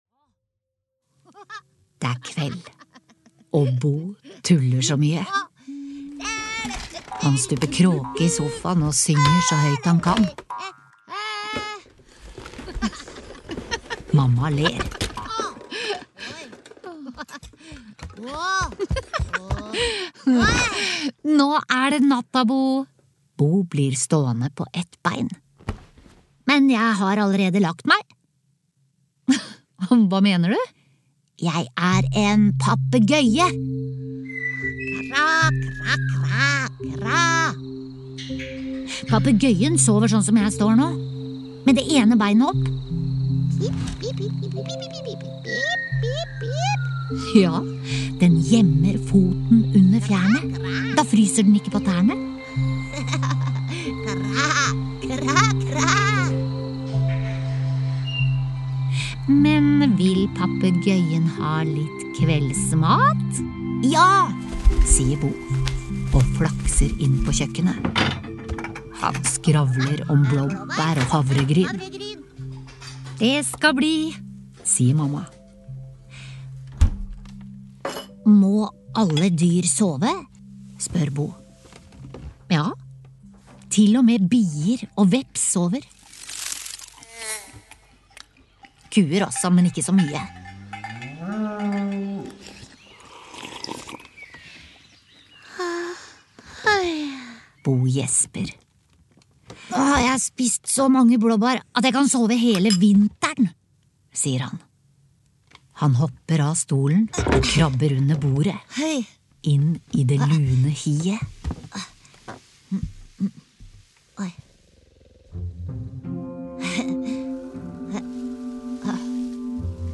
Beriket med avslappende og stemningsskapende lyd og musikk er «Dyrene sover» en vakker og undrende lydbok som passer perfekt for nysgjerrige barn.